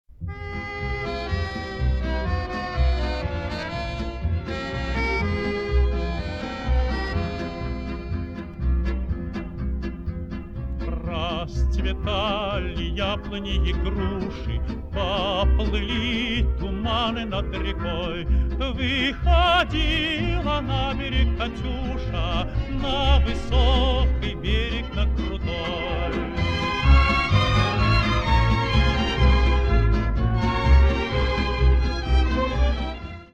оркестр , народные
ретро